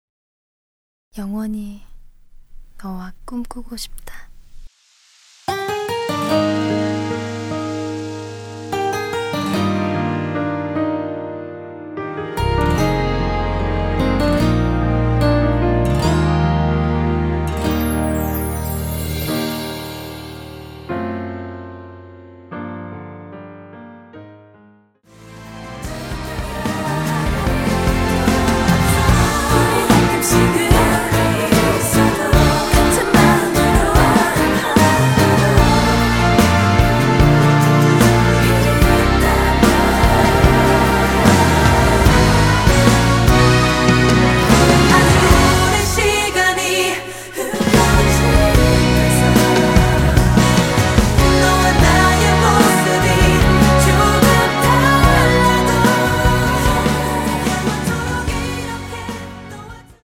원키에서(-1)내린 코러스 포함된 MR이며 미리듣기로 확인후 구매 하여 주세요
Eb
◈ 곡명 옆 (-1)은 반음 내림, (+1)은 반음 올림 입니다.
앞부분30초, 뒷부분30초씩 편집해서 올려 드리고 있습니다.
중간에 음이 끈어지고 다시 나오는 이유는